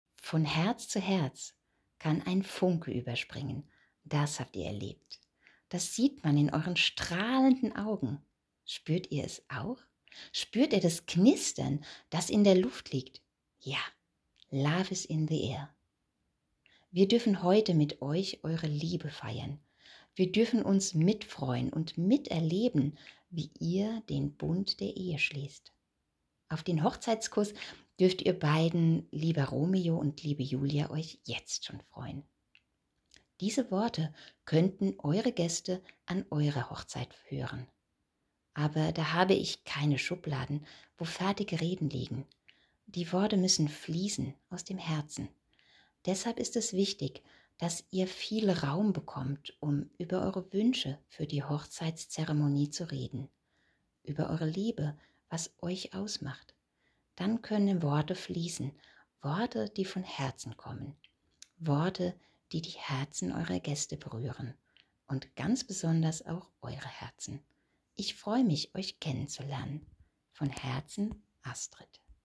So klingt meine Stimme